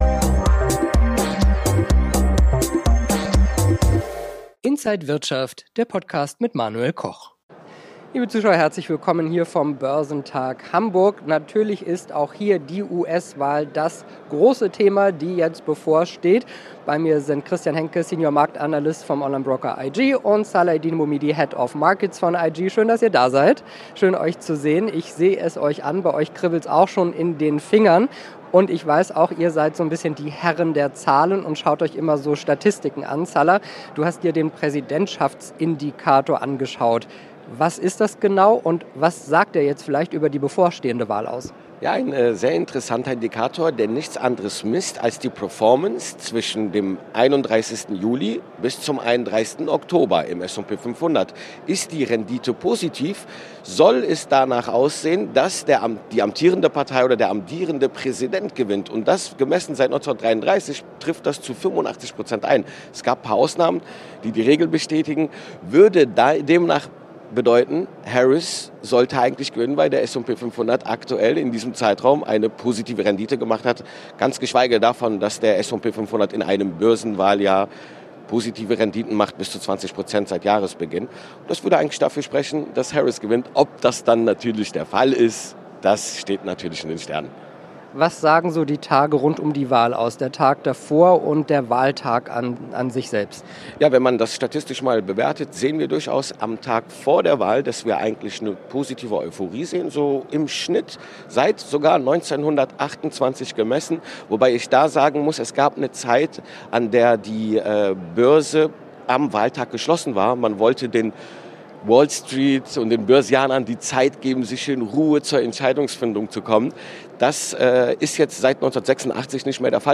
Alle Details im Interview